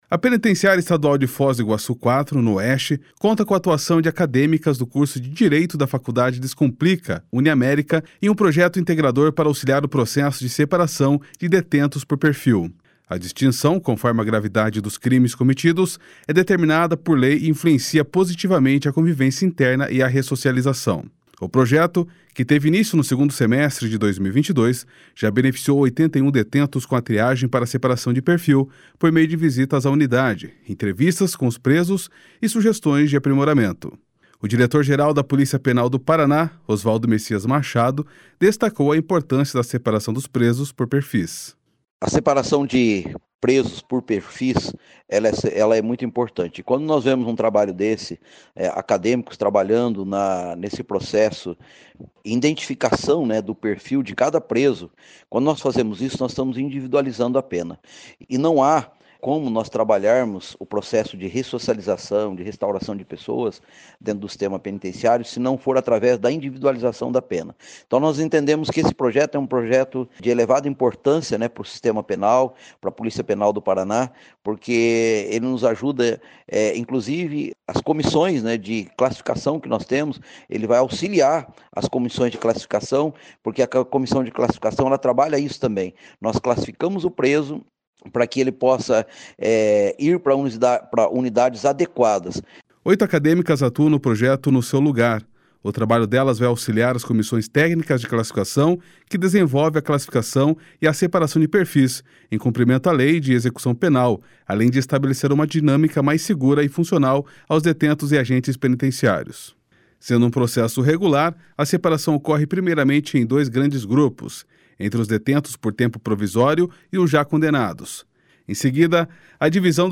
O projeto, que teve início no segundo semestre de 2022, já beneficiou 81 detentos com a triagem para separação de perfil, por meio de visitas à unidade, entrevistas com os presos e sugestões de aprimoramento. O diretor-geral da Polícia Penal do Paraná, Osvaldo Messias Machado, destacou a importância da separação dos presos por perfis. //SONORA OSVALDO MACHADO//